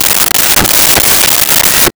Saw Wood 04
Saw Wood 04.wav